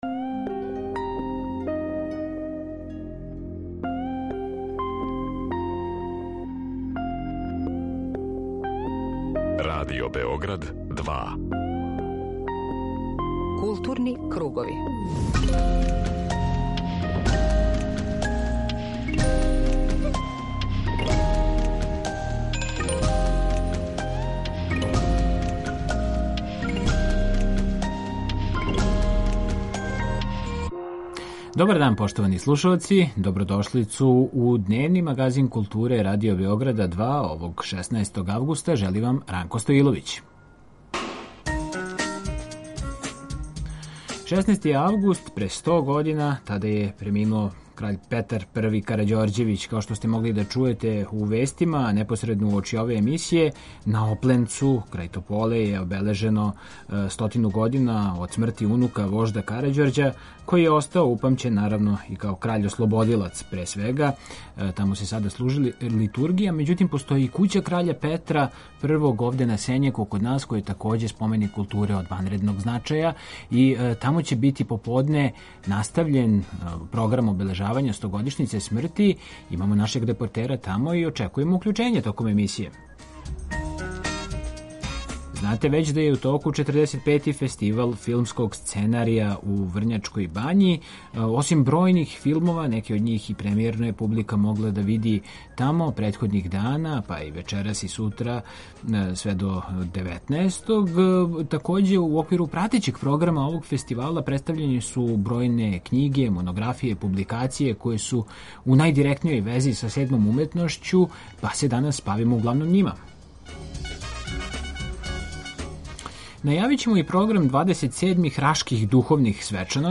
Поред богатог филмског програма, представљене су и значајне публикације, монографије и књиге о филму. Данас ће у Кући краља Петра Првог на Сењаку бити обележен један век од смрти краља Петра Првог Карађорђевића, па ћемо чути каква је атмосфера у дворишту овог споменика културе непосредно уочи свечаности.